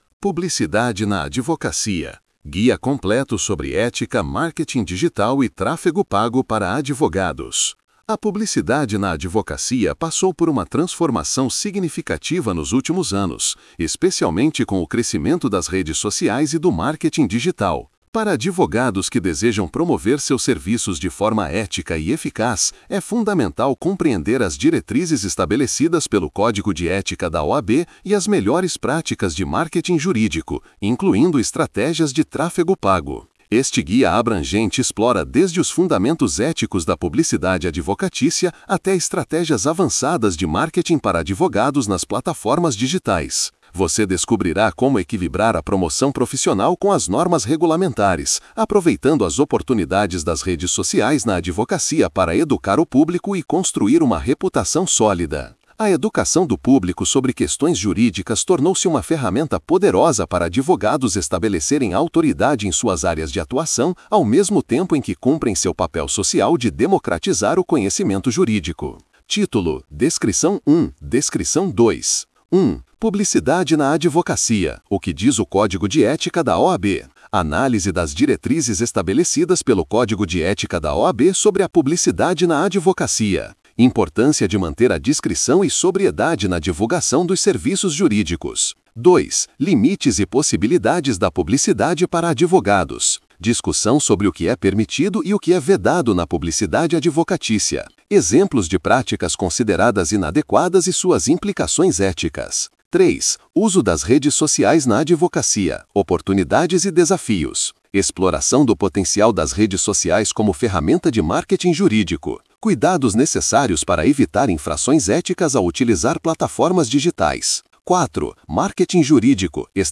Ouça esse Artigo em Aúdio